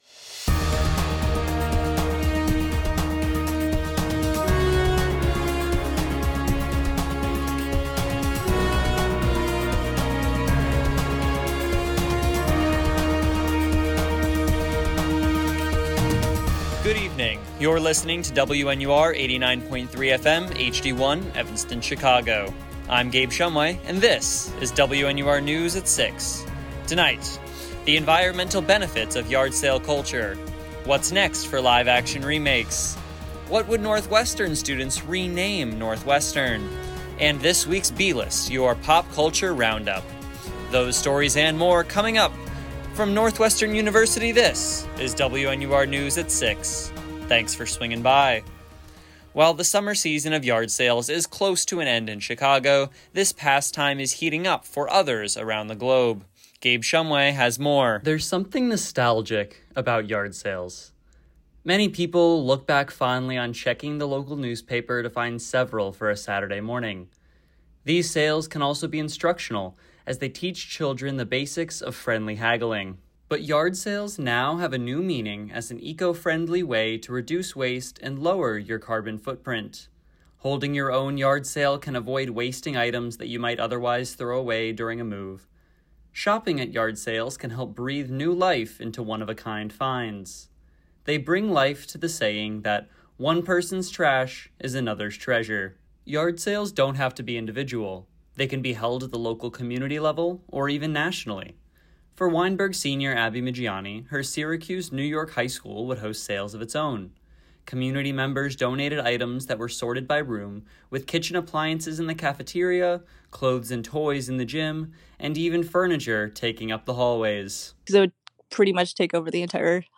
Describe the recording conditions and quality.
October 6, 2025: The environmental benefits of yard sale culture, what’s next for live-action remakes , what would Northwestern students rename Northwestern?, and this week’s b-list, your pop culture roundup WNUR News broadcasts live at 6 pm CST on Mondays, Wednesdays, and Fridays on WNUR 89.3 FM.